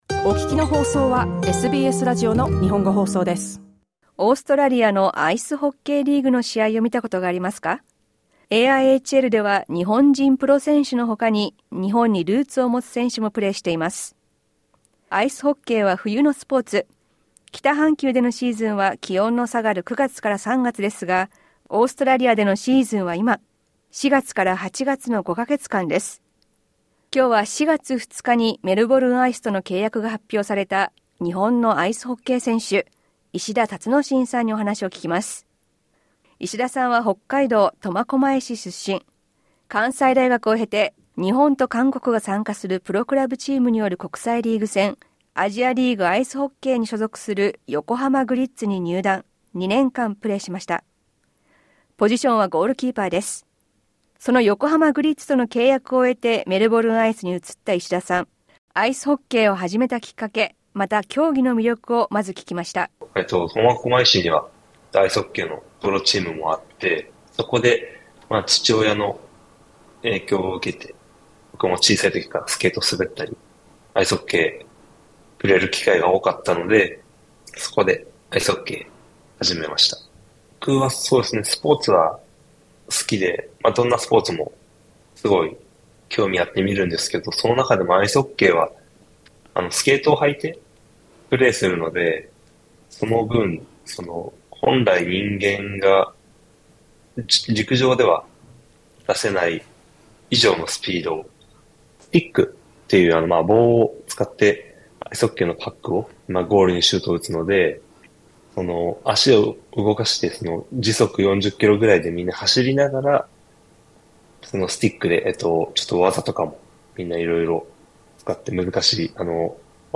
インタビューでは、アイスホッケーとの出会いやその楽しさ、良いゴールキーパーの資質、始まったばかりのオーストラリア生活などについて聞きました。